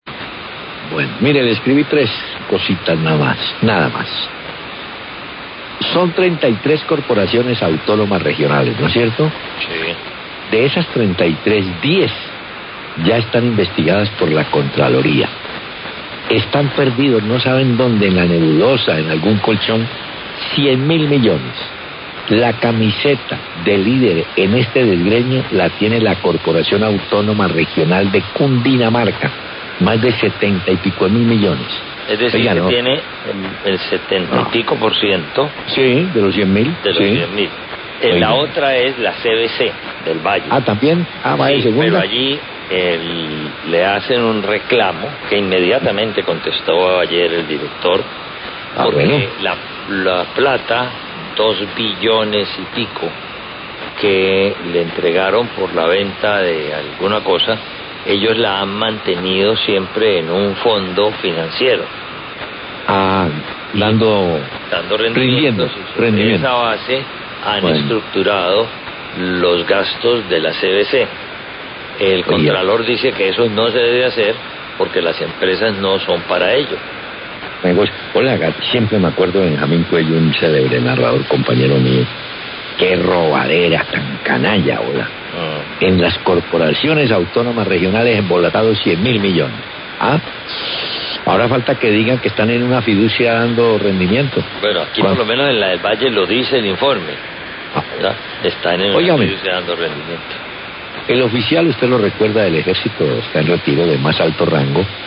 Radio
Comentario de Gustavo Álvarez Gardeazabal y Hernán Pelaez sobre al denuncia de la Contraloría General de la Nación que 10 de las 33 Corporaciones Autónomas Regionales del país, estan siendo investigadas por la pérdida de cerca de 100 mil millones de pesos.